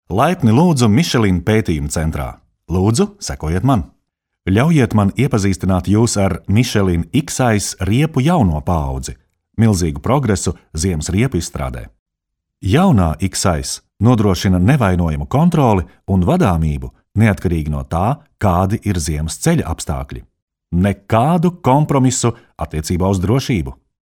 Erkek Ses